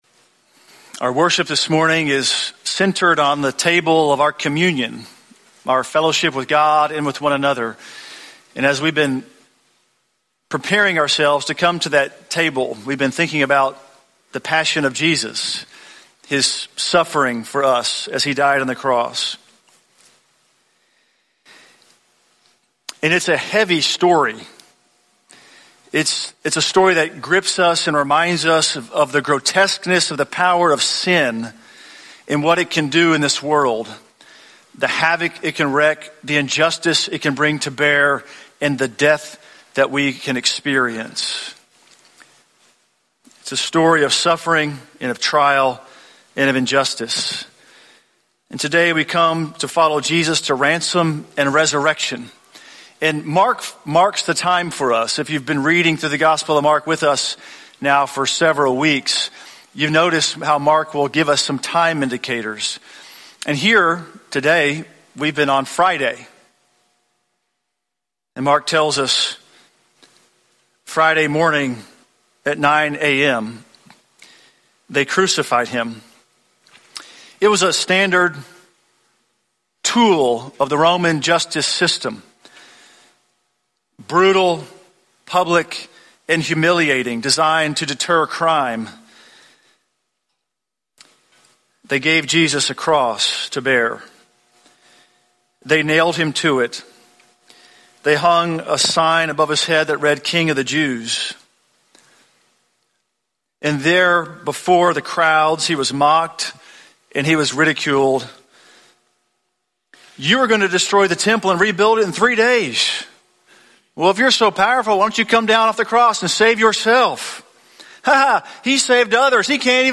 Sermon Archives
From Series: "English Sermons - 10:15"